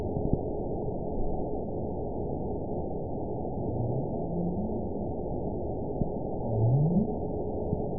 event 922221 date 12/28/24 time 11:09:29 GMT (4 months ago) score 8.96 location TSS-AB04 detected by nrw target species NRW annotations +NRW Spectrogram: Frequency (kHz) vs. Time (s) audio not available .wav